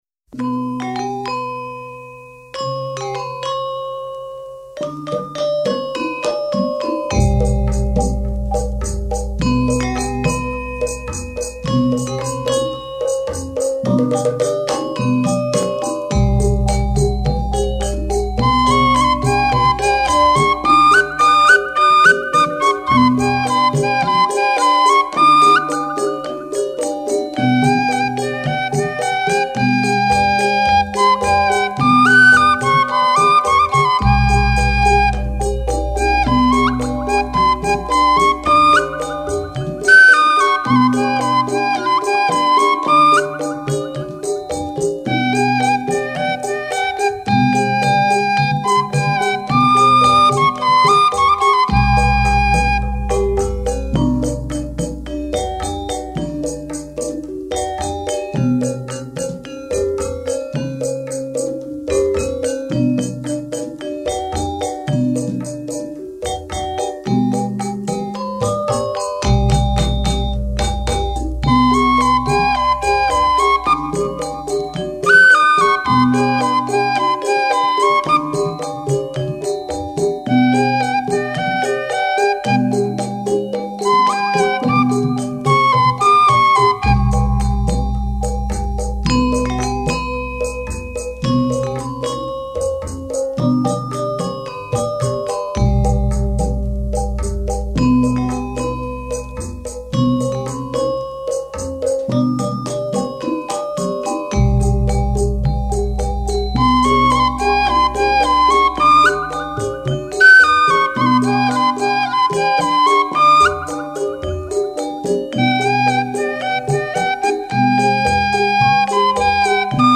صدای زنگوله